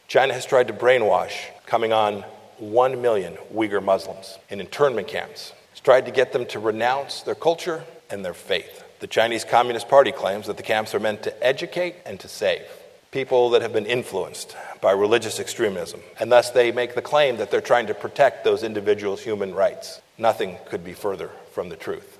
The 190th Landon Lecture was delivered Friday by US Secretary of State and former Kansas Congressman Mike Pompeo.
Speaking for about 20 minutes to a packed house at K-State’s McCain Auditorium, Pompeo spoke about refocusing American diplomacy on the promotion of unalienable human rights.